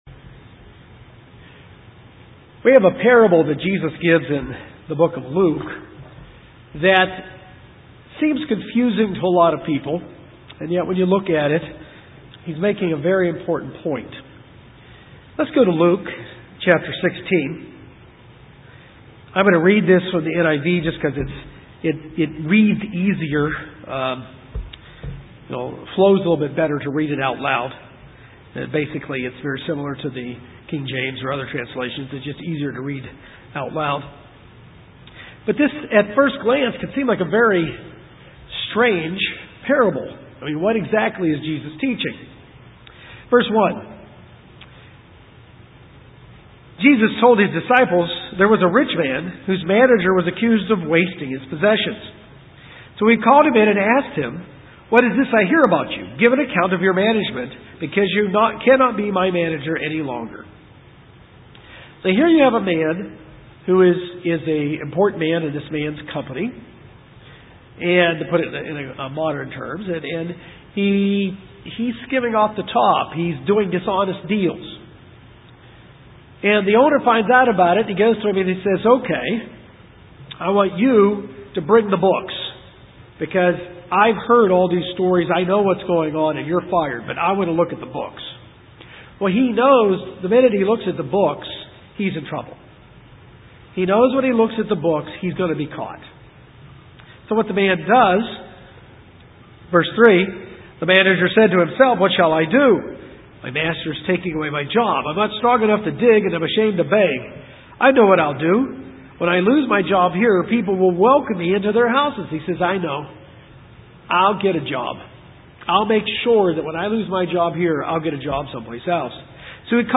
In this sermon we learn how we should manage our wealth. How we manage our finances shows God how we will handle what God has to give us in the future.